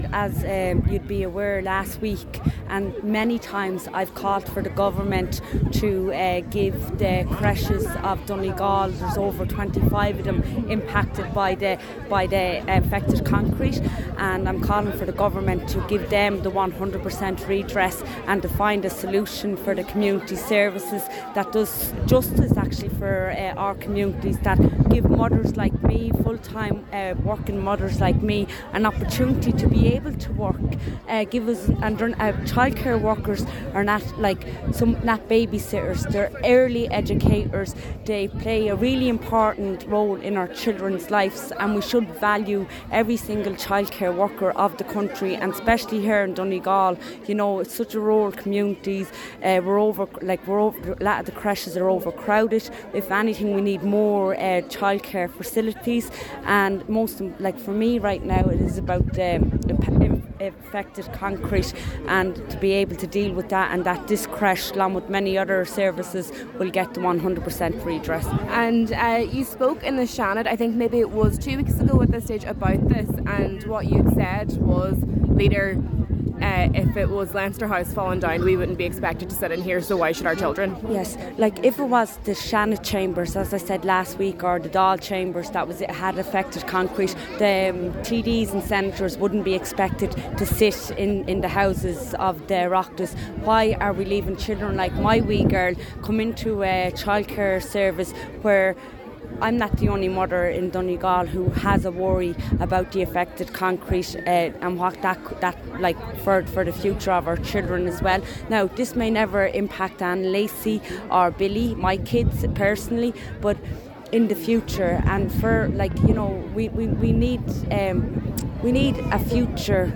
Senator Eileen Flynn says the visit is significant as she calls for 100% redress for childcare facilities: